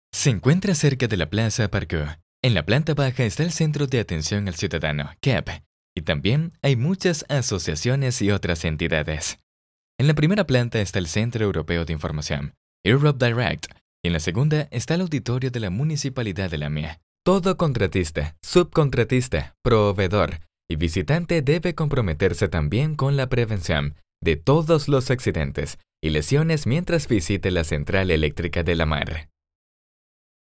Espagnol (Amérique Latine)
Commerciale, Jeune, Naturelle, Enjouée, Corporative
Guide audio